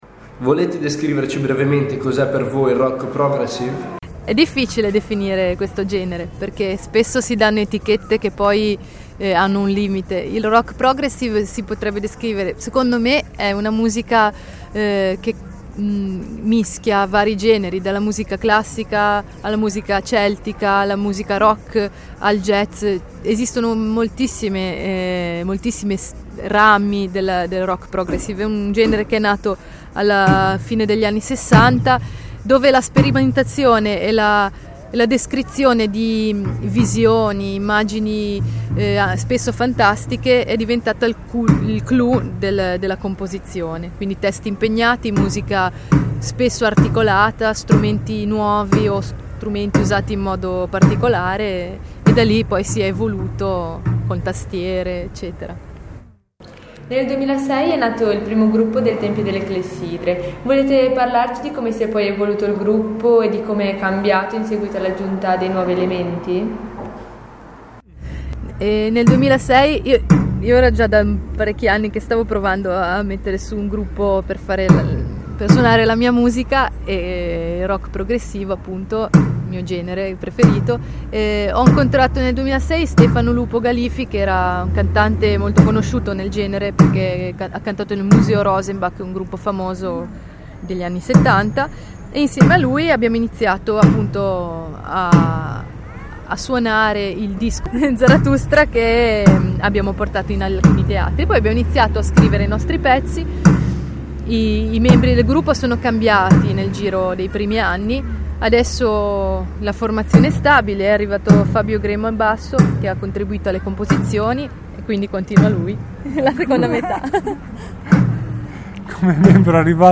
Il gruppo ha suonato in un concerto oganizzato dal nostro Centro Giovani in piazza a Santa Margherita Ligure ed è stato intervistato durante il sound-check al pomeriggio.